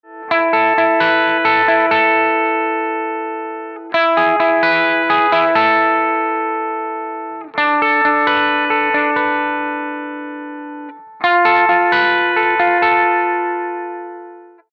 GR5 Preset – WALK (Clean Solo)